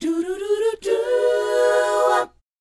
DOWOP C#AU.wav